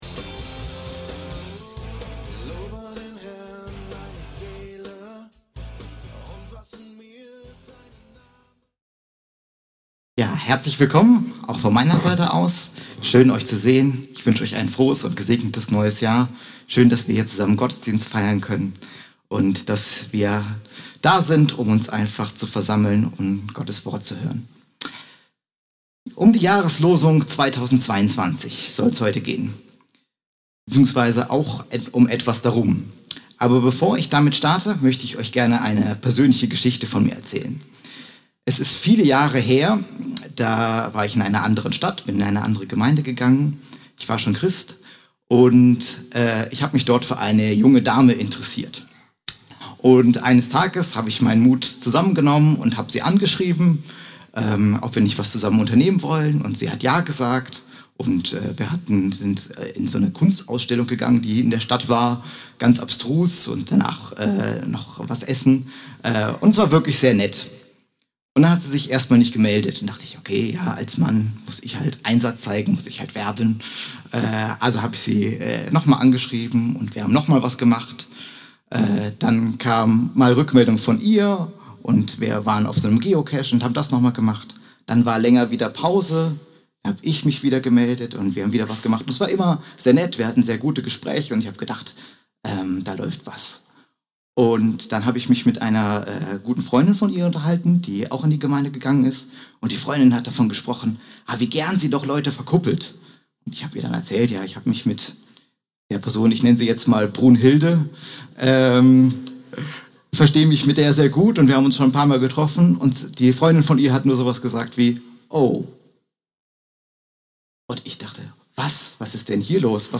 Gottesdienst: Die Jahreslosung – Kirche für Bruchsal